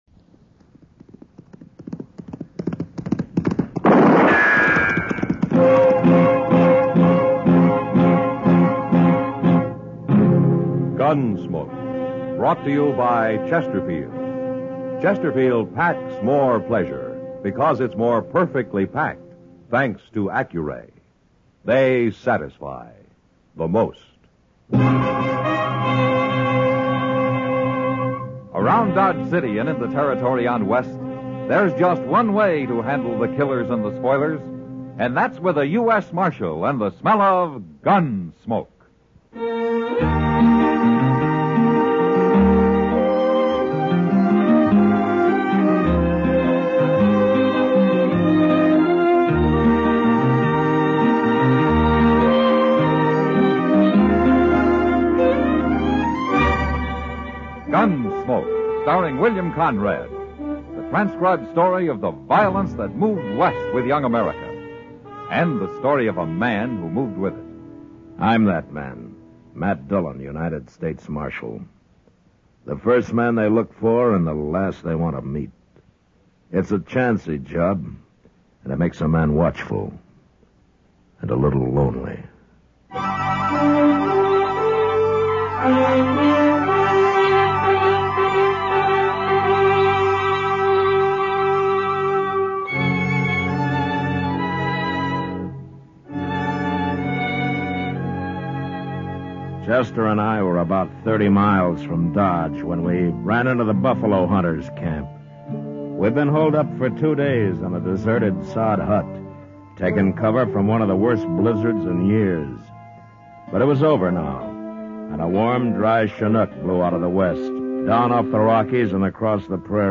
Alan Young Show - (Comedy)